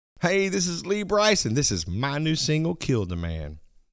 Liners